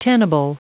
Prononciation du mot tenable en anglais (fichier audio)
Prononciation du mot : tenable